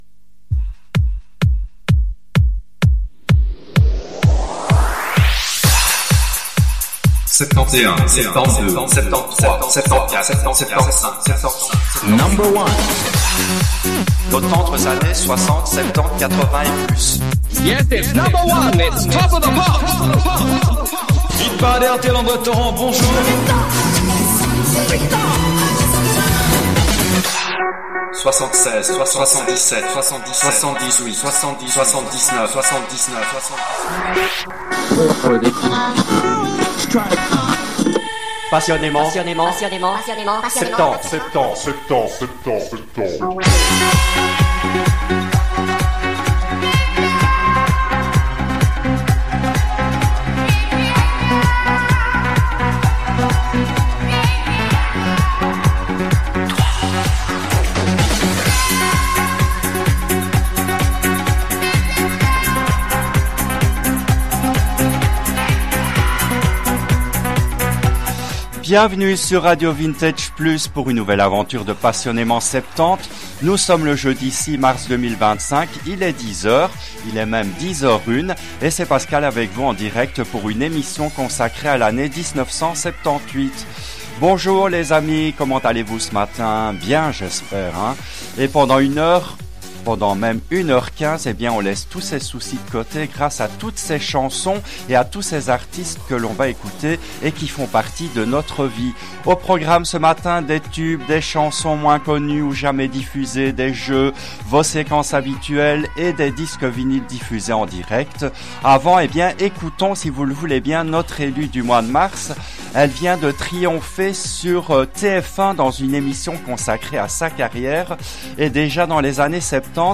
L’émission a été diffusée en direct le jeudi 06 mars 2025 à 10h depuis les studios belges de RADIO RV+.